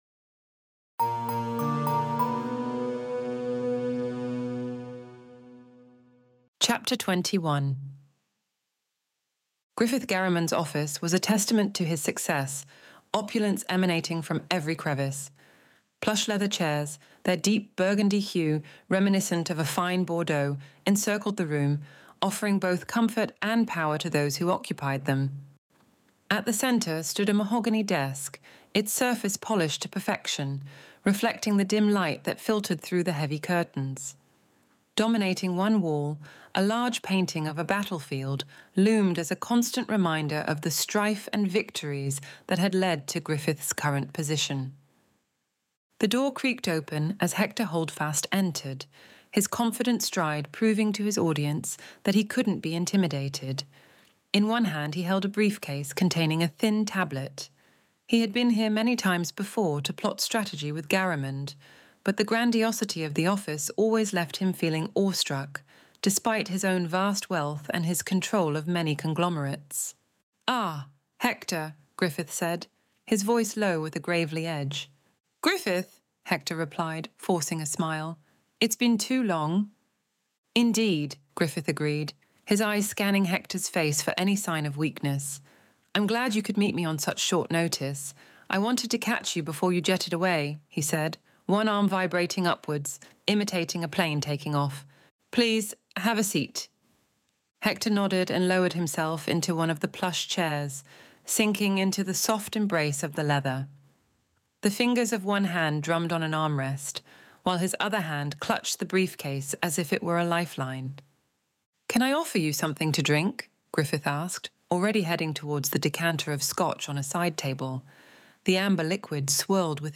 Extinction Event Audiobook Chapter 21